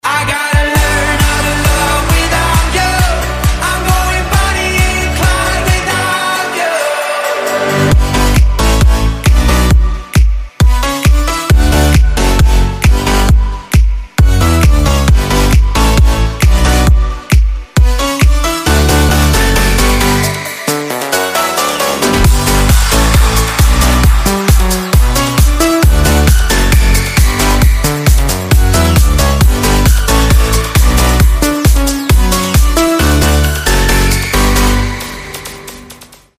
• Качество: 320, Stereo
мужской вокал
зажигательные
dance
EDM
Жанр: Progressive House